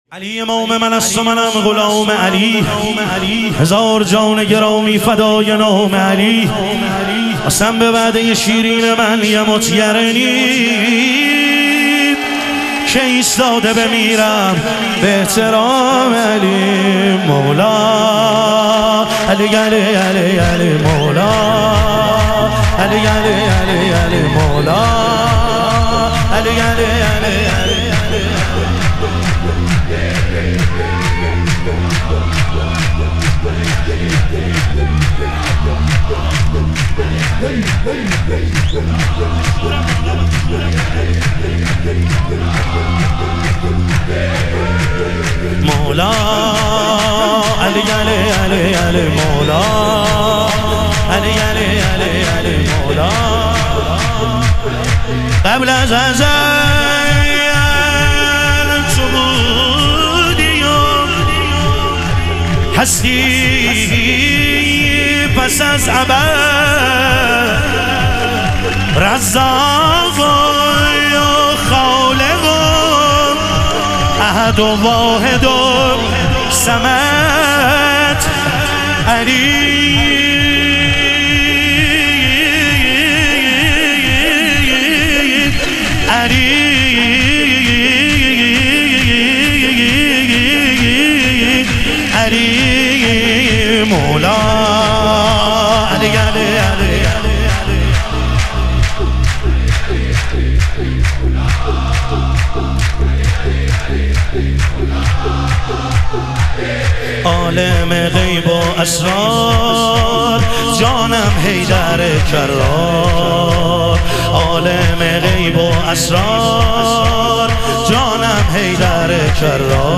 ظهور وجود مقدس حضرت مهدی علیه السلام - شور